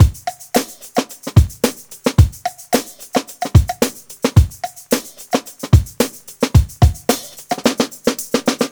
Unison Funk - 6 - 110bpm.wav